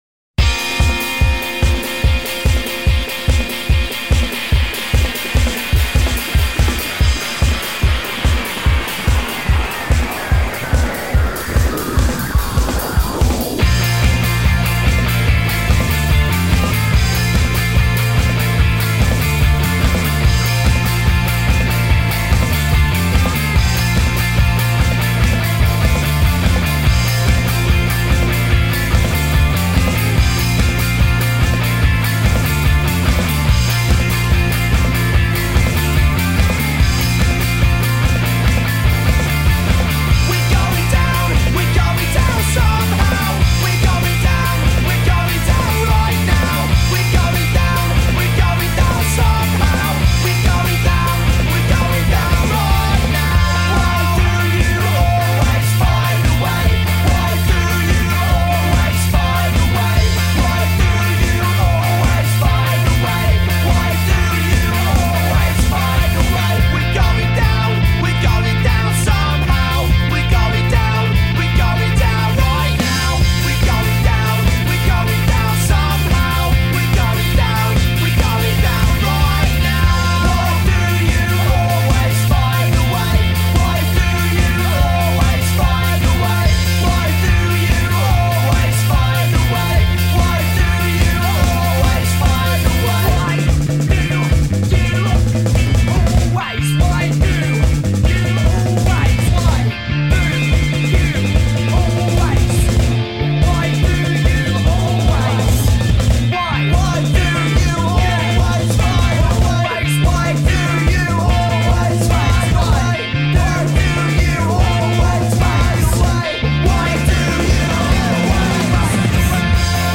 bajo y voz
guitarra y voz
batería y voz
maquinitas y voz
es el indie rock de toda la vida